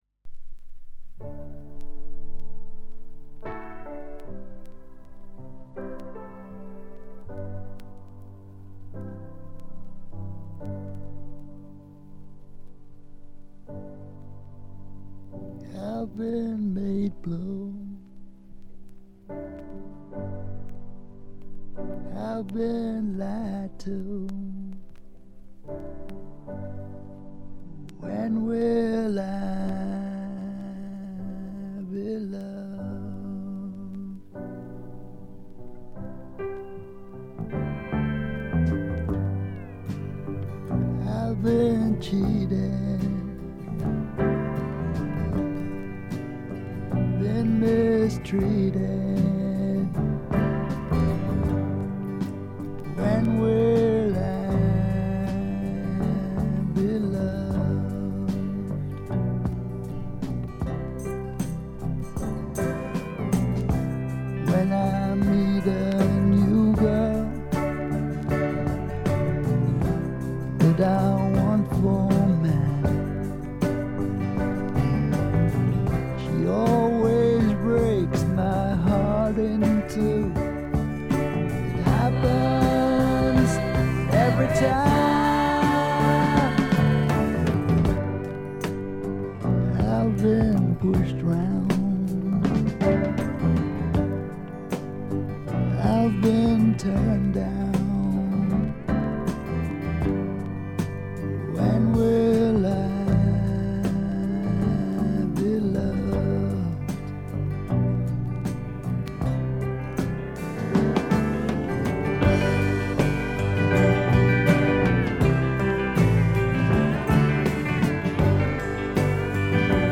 ホーム > レコード：英国 SSW / フォークロック
A1序盤とB1冒頭でチリプチがやや目立ちますが、これ以外は静音部での軽微なノイズ程度。
試聴曲は現品からの取り込み音源です。